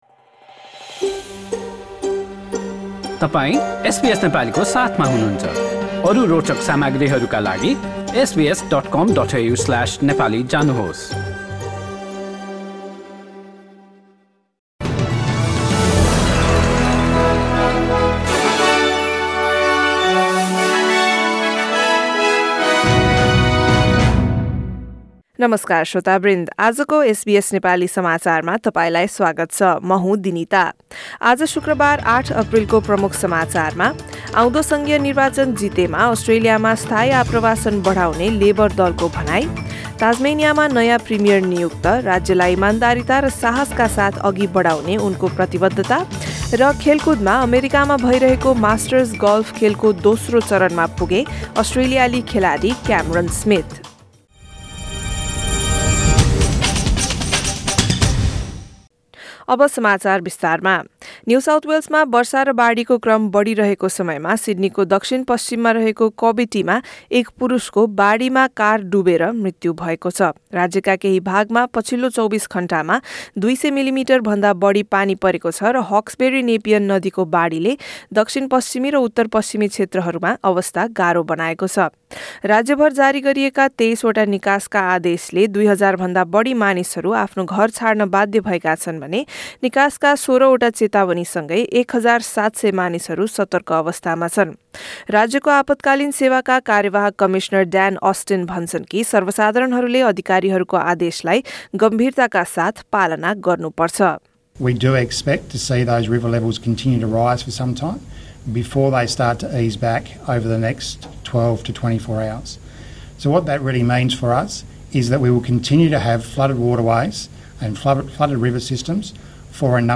एसबीएस नेपाली अस्ट्रेलिया समाचार: शुक्रबार ८ अप्रिल २०२२